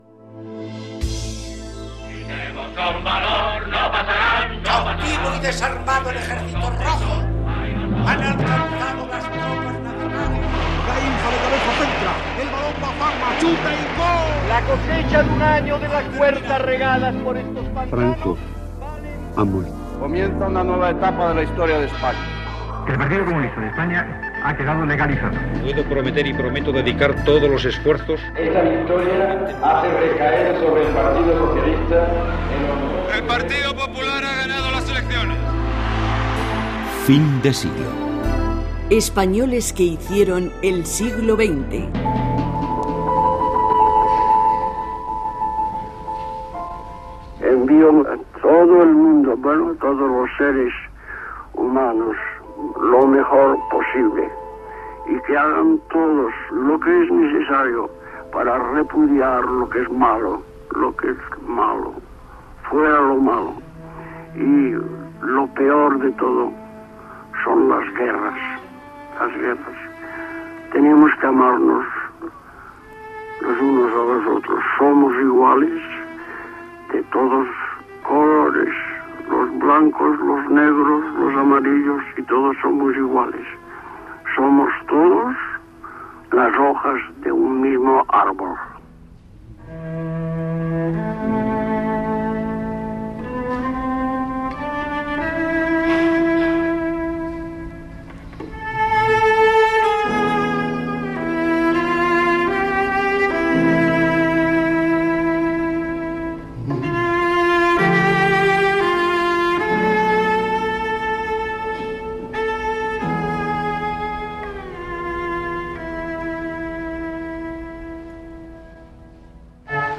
Careta del programa, espai dedicat al músic Pau Casals. Paraules de Pau Casals, "El cant dels ocells", dades biogràfiques: la seva infantesa a El Vendrell
Divulgació